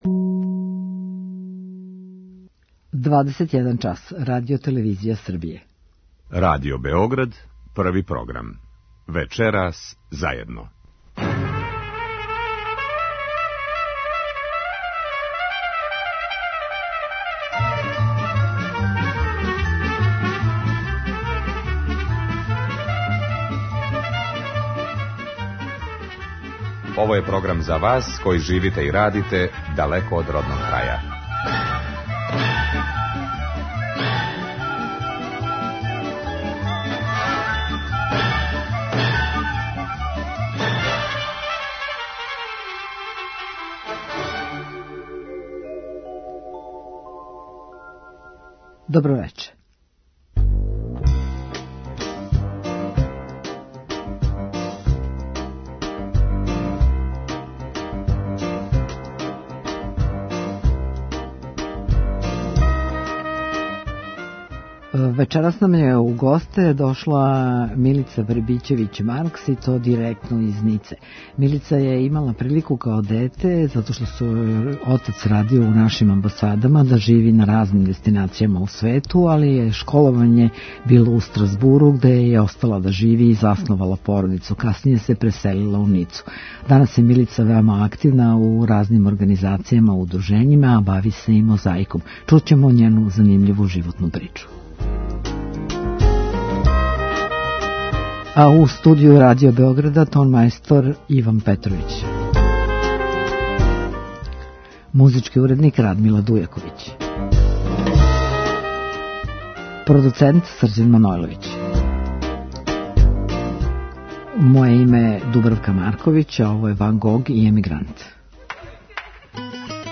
О свом животу у Француској, активностима и уметности разговара ћемо вечерас са нашом гошћом.
Емисија магазинског типа која се емитује сваког петка од 21 час.